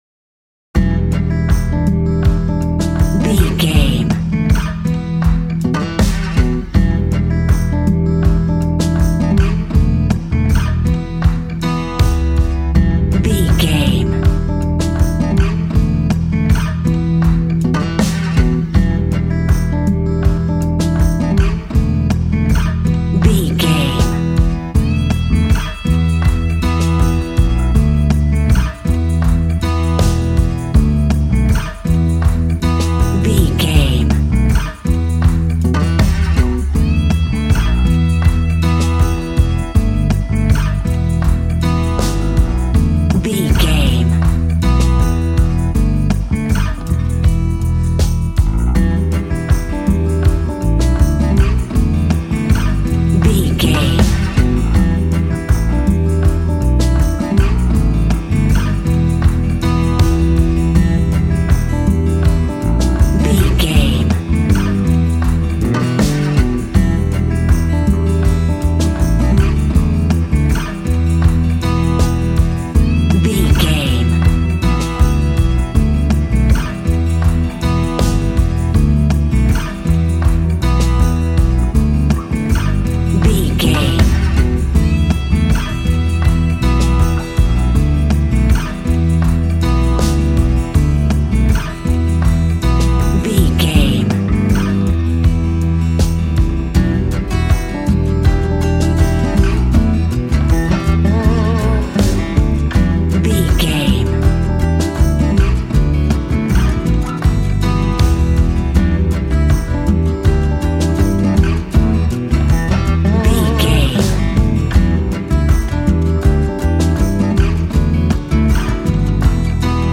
Aeolian/Minor
romantic
sweet
happy
acoustic guitar
bass guitar
drums